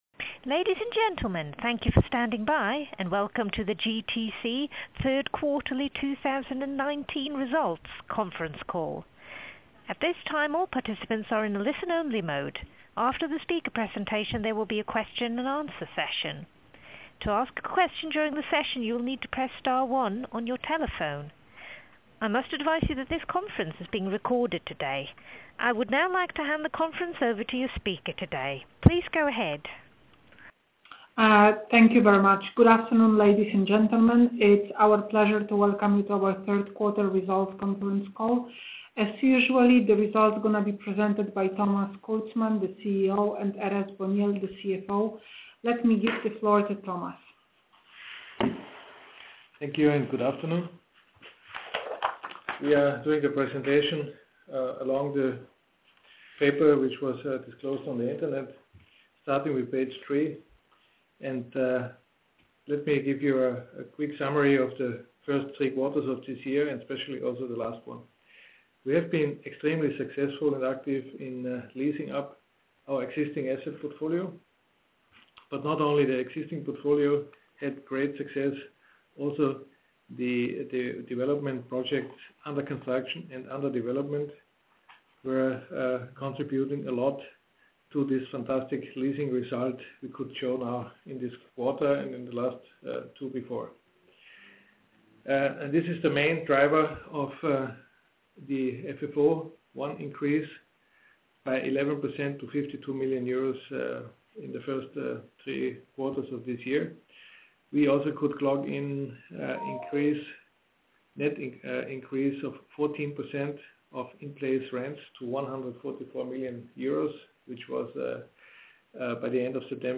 Wyniki za III kw. i 9 m-cy 2019 r. (telekonferencja w języku angielskim)